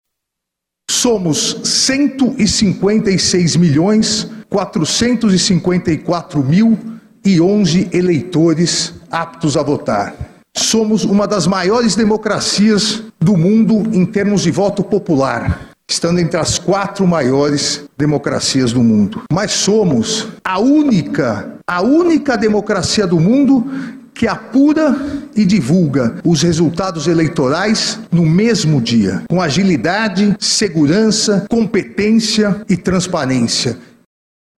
O presidente do TSE, Alexandre de Moraes, na sua posse já relatava a importância e segurança do pleito.
Sonora-Alexandre-de-Moraes-presidente-do-TSE.mp3